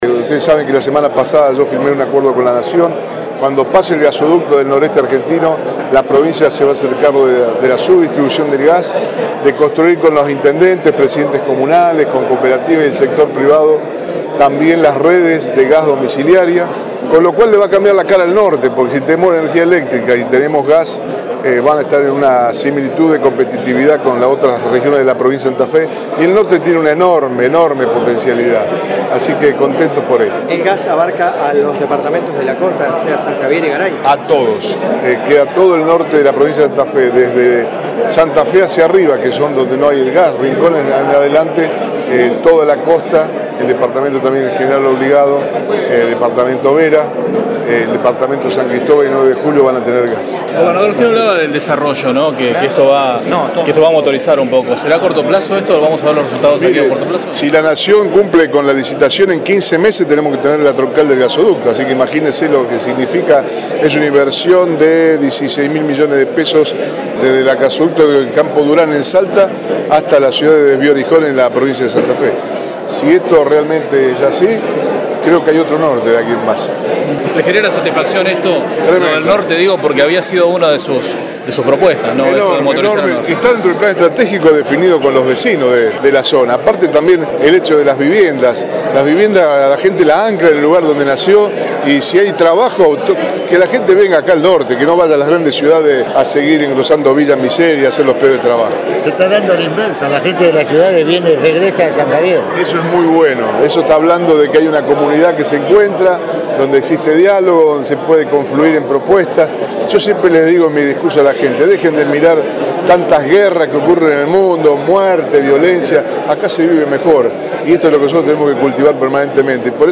Declaraciones de Bonfatti sobre el impacto del gasoducto del NEA en la región